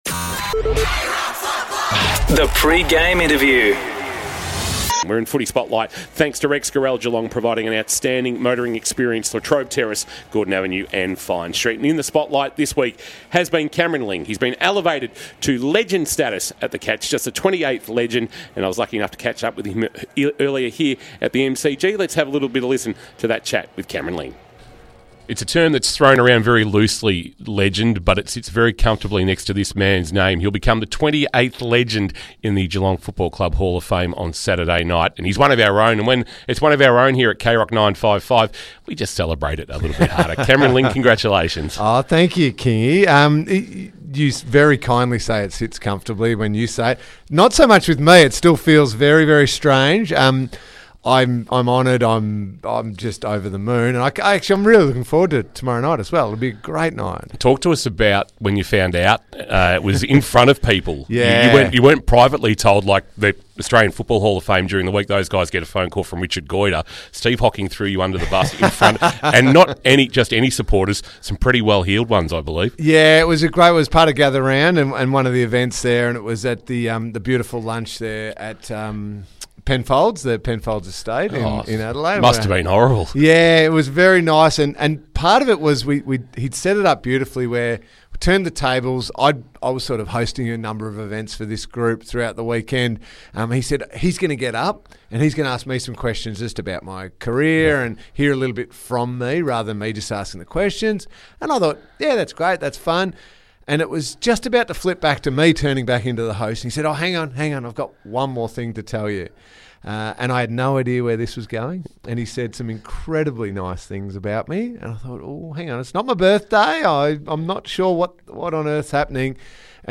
2024 - AFL - Round 15 - Carlton vs. Geelong: Pre-match interview - Cameron Ling (Geelong Legend)